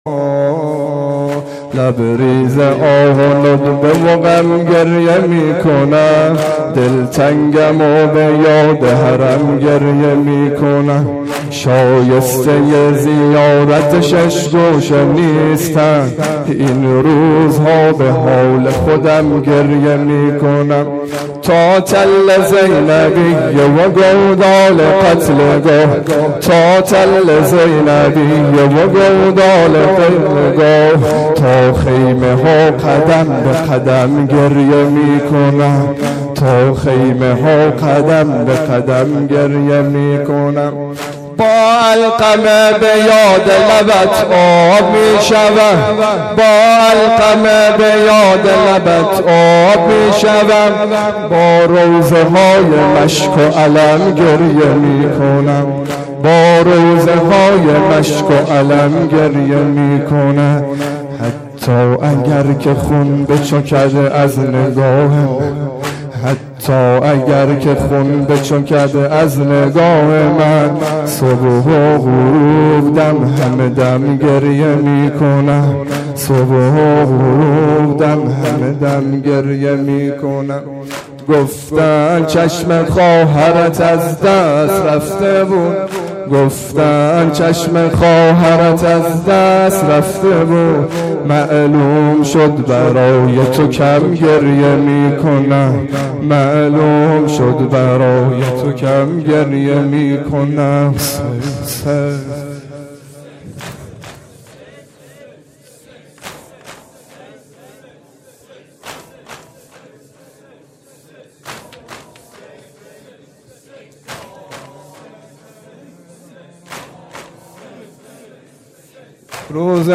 شب-عاشورا-واحد-تند.mp3